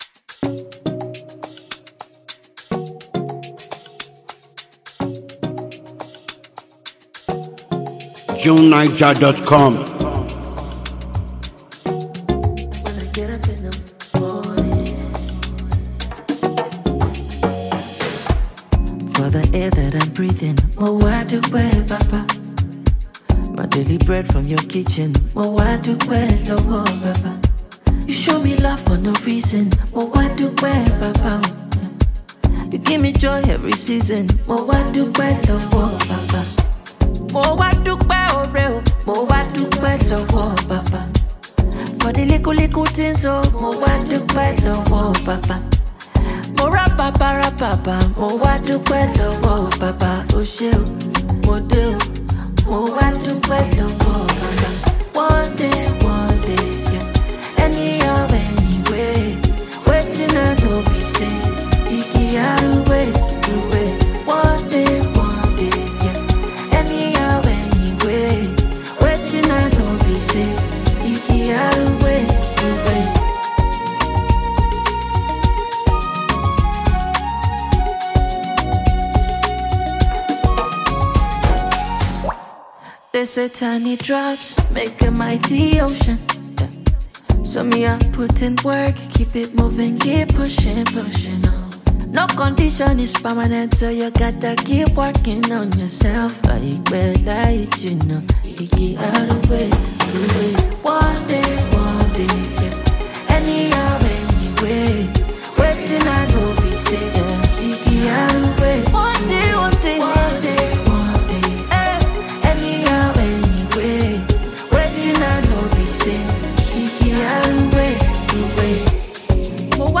captivates audiences with his latest seductive ballad